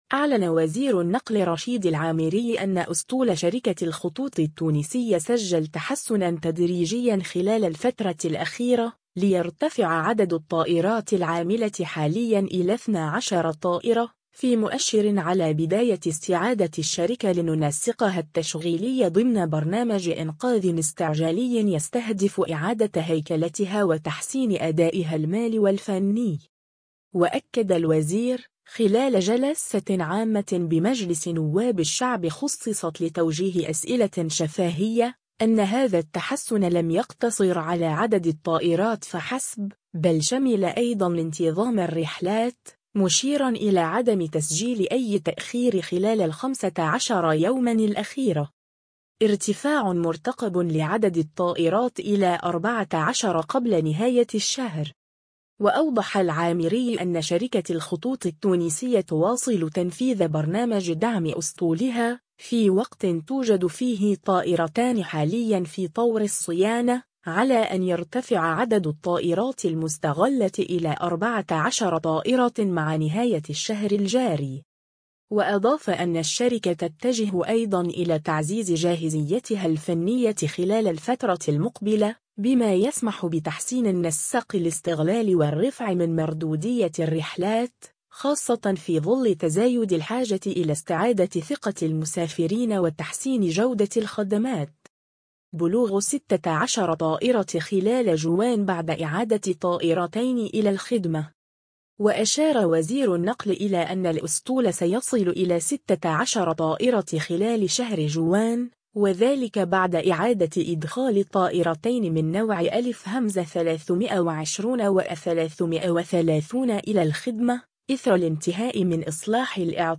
وأكد الوزير، خلال جلسة عامة بمجلس نواب الشعب خُصصت لتوجيه أسئلة شفاهية، أن هذا التحسن لم يقتصر على عدد الطائرات فحسب، بل شمل أيضا انتظام الرحلات، مشيرا إلى عدم تسجيل أي تأخير خلال الخمسة عشر يوما الأخيرة.